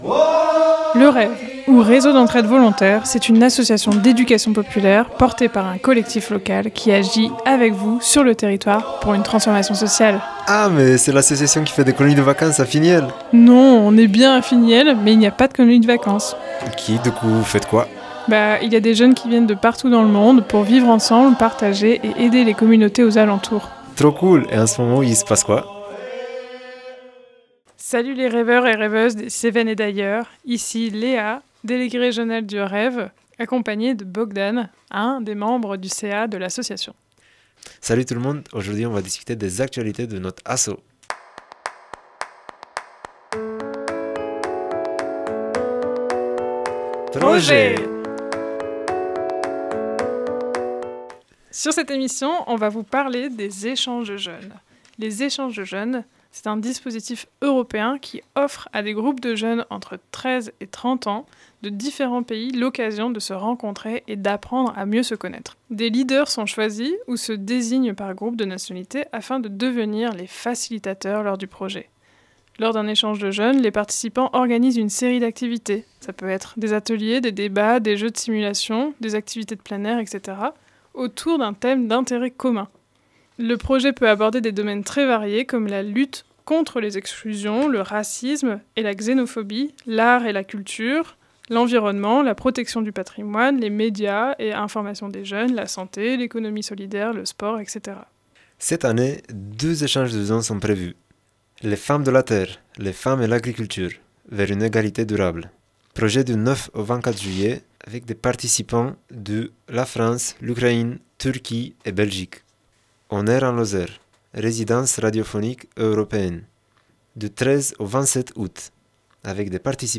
C’est une émission animée par les membres de l’association REV (pour Réseau d’Entraide Volontaire), consacrée à l’interculturalité, l’intergénérationnalité, au vivre ensemble et à l’émancipation individuelle par le collectif. Info, actus, projets et initiatives, tout ce qu’il faut savoir pour vous donner envie de rejoindre l’aventure!